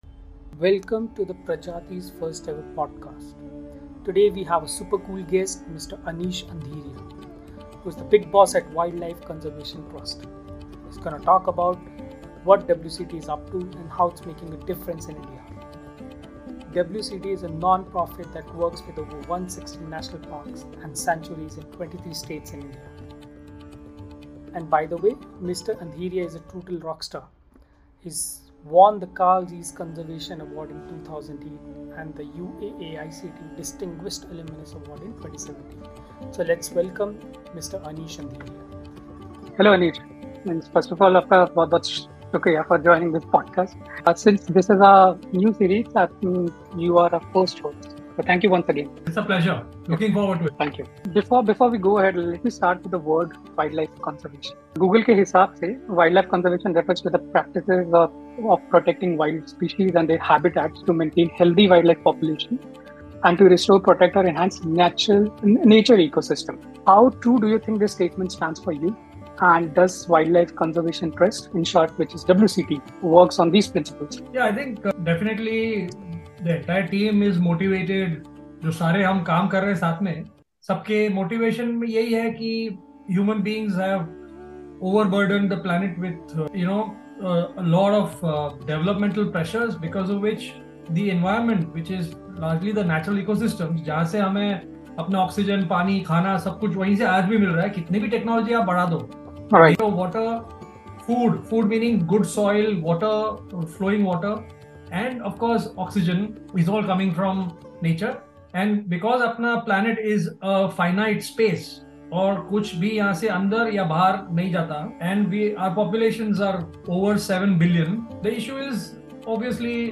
This podcast takes you on a journey through the intriguing world of animal behavior, delving deep into the behaviors and habits of a wide range of species. Featuring interviews with leading researchers and experts in the field, this podcast provides a unique insight into the ways in which animals interact with their environment, each other, and even humans.